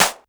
Snare_06.wav